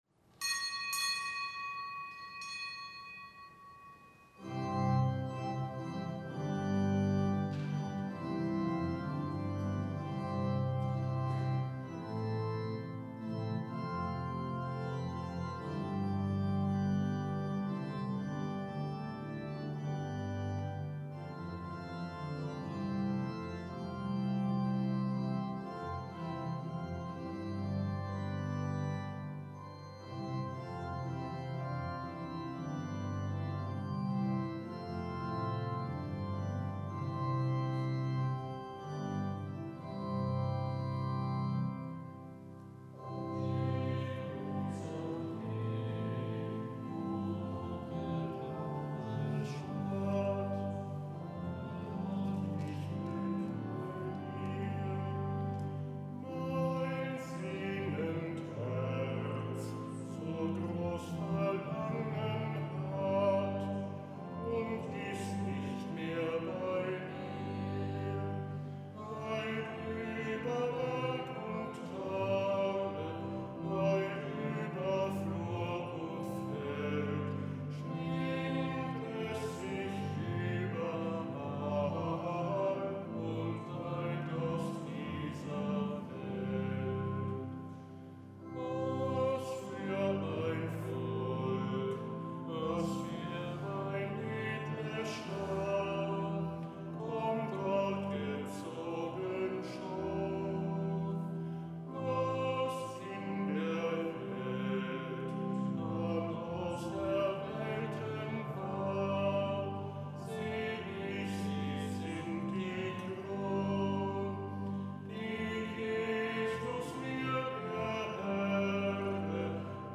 Kapitelsmesse am Donnerstag der vierunddreißigsten Woche im Jahreskreis